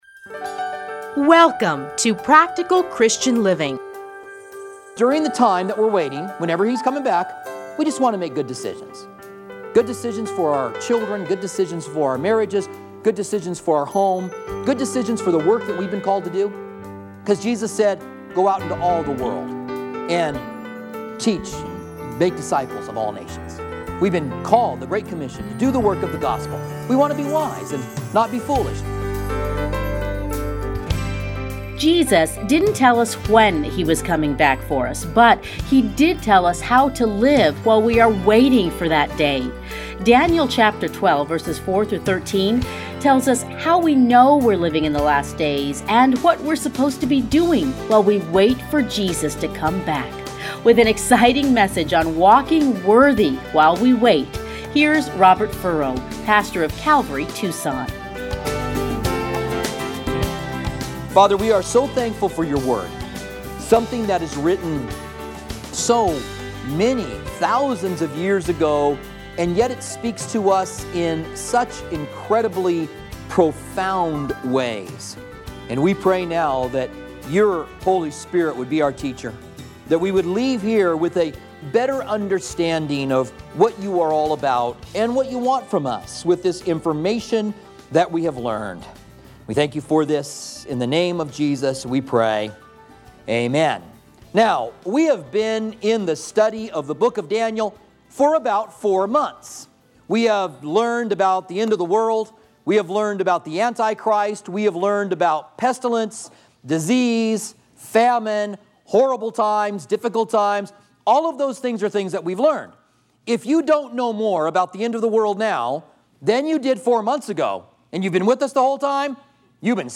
teachings are edited into 30-minute radio programs titled Practical Christian Living.